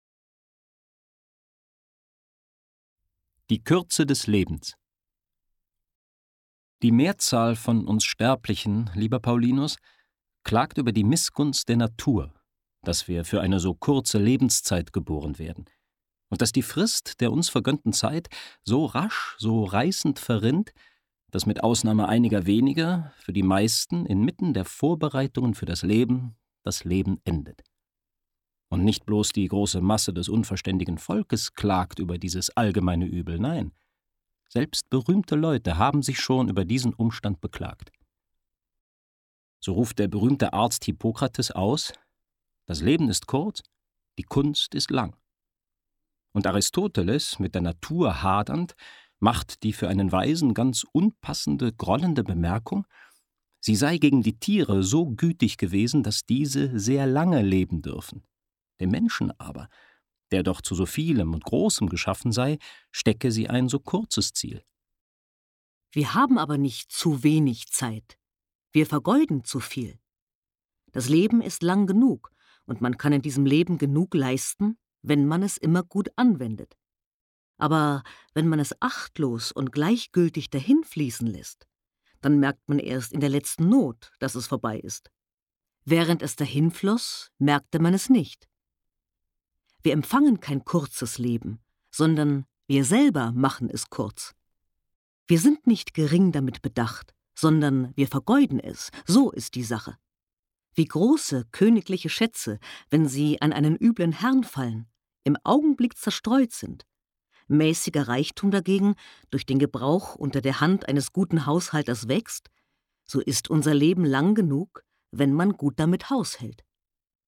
Erstmals wird dieser Text als Hörbuch durch zwei Sprecher dialogisch zum Leben erweckt.